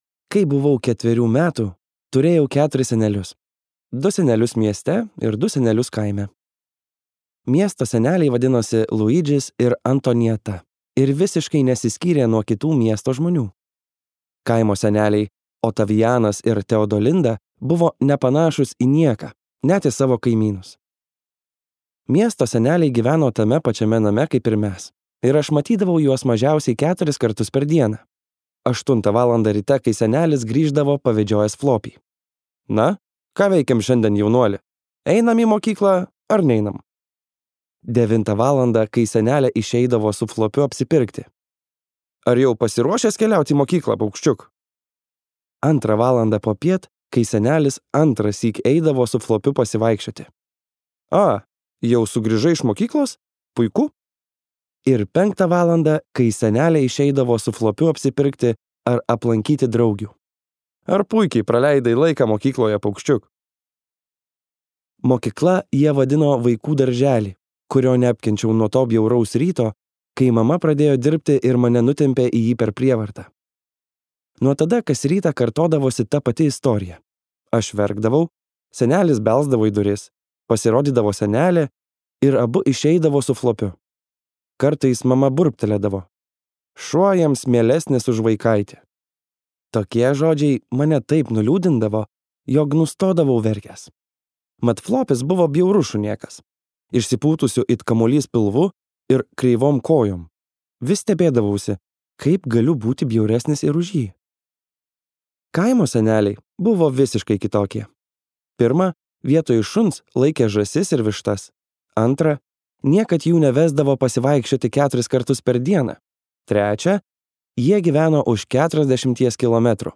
Mano senelis buvo vyšnia | Audioknygos | baltos lankos